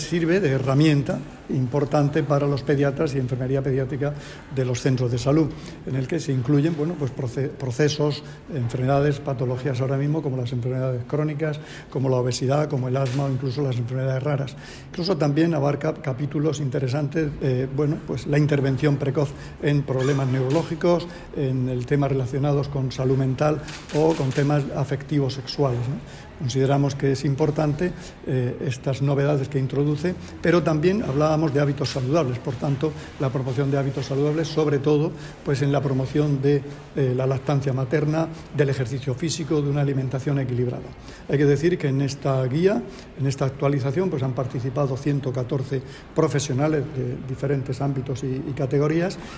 Declaraciones del consejero de Salud, Juan José Pedreño, sobre las novedades de la guía de apoyo al Programa de Atención al Niño y al Adolescente (PANA).